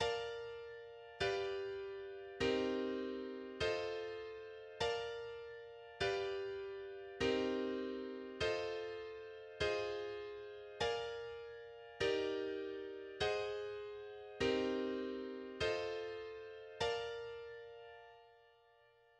The B section is followed by a final A section